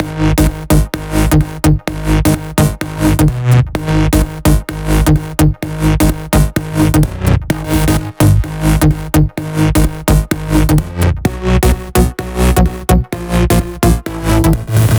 VFH3 128BPM Funkalicious Melody 1.wav